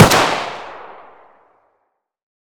glock20_fire1.wav